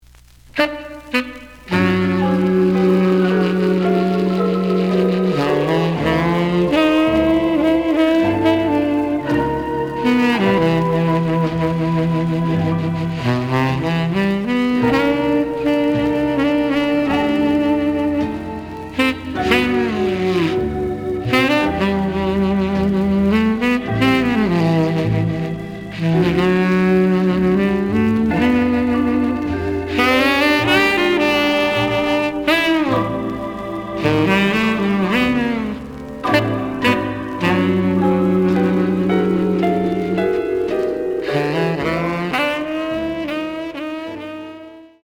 The audio sample is recorded from the actual item.
●Genre: Jazz Other
Looks good, but slight noise on both sides.)